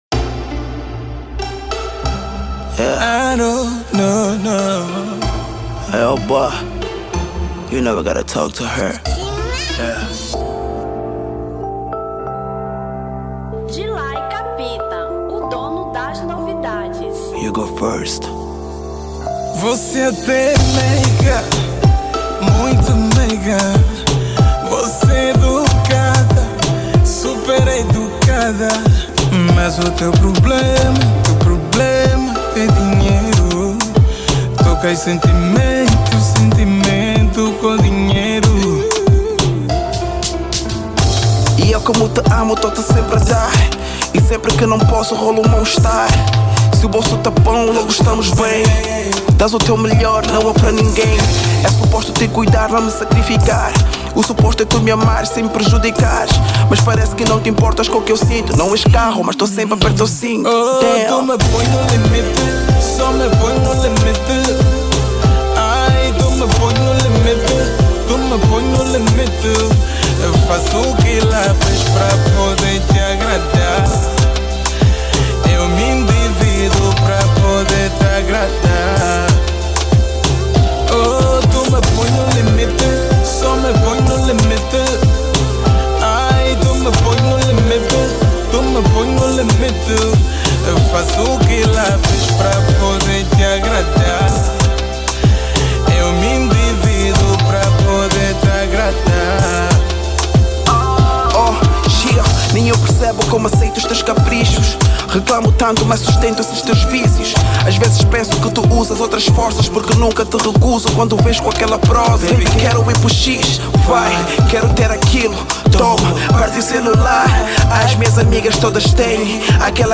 Zouk 2017